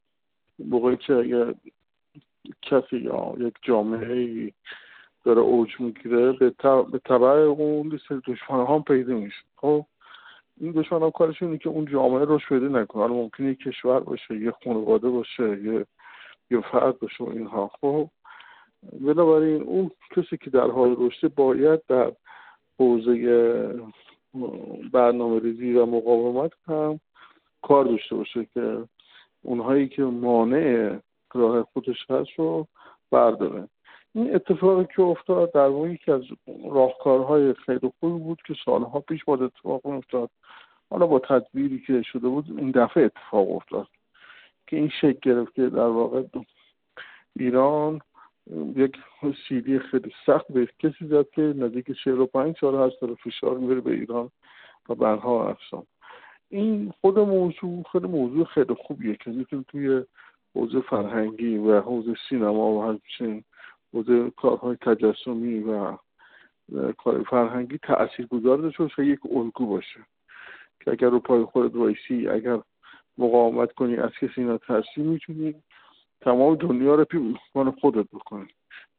کارگردان سینما و تلویزیون در گفت‌وگو با خبرنگار ایکنا